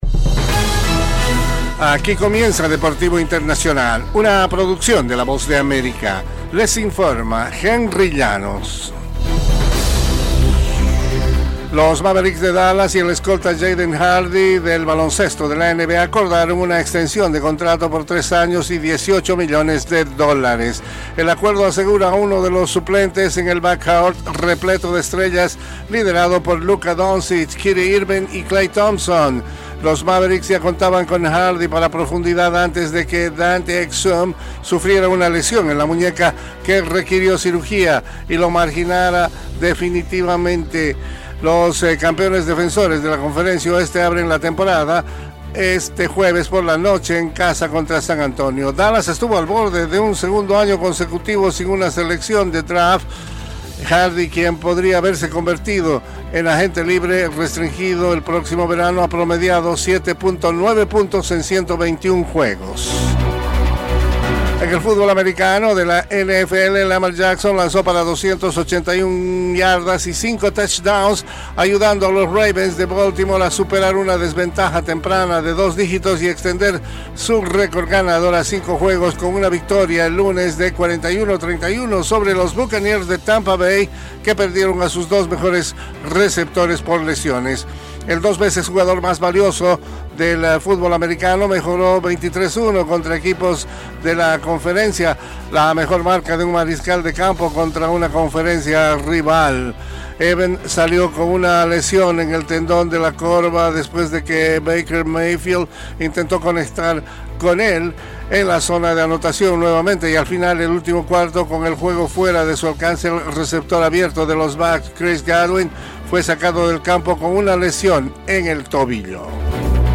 Las noticias deportivas llegan desde los estudios de la Voz de América en la voz de